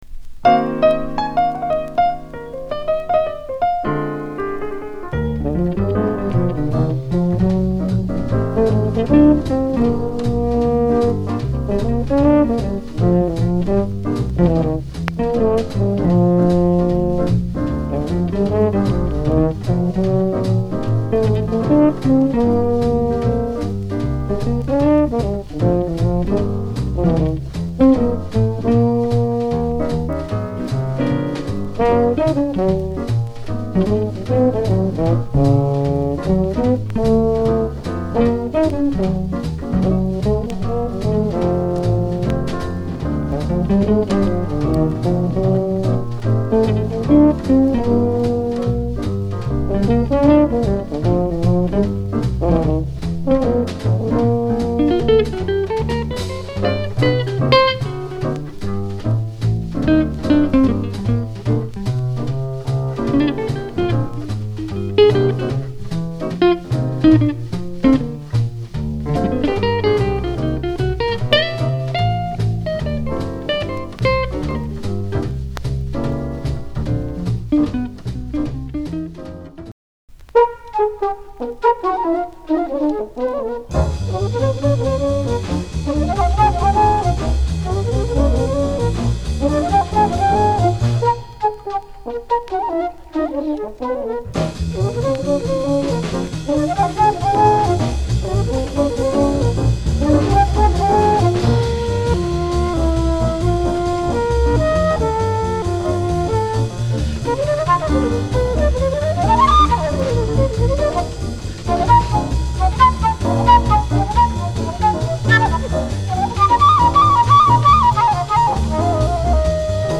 discription:Mono橙両溝
ギターはそこまでではありませんが、フルートはかなりの腕前です。